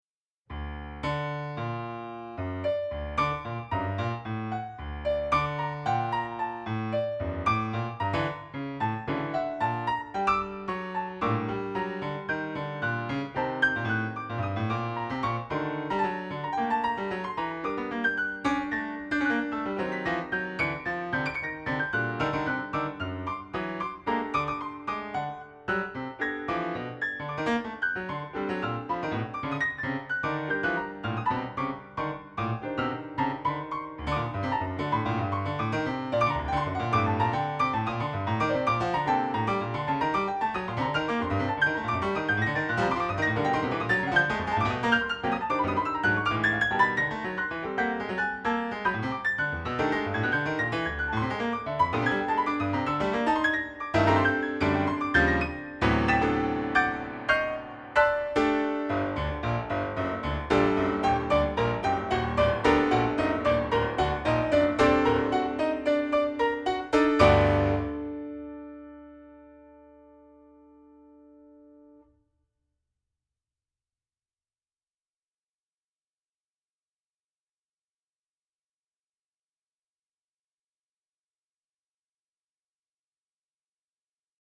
Yamaha Disklavier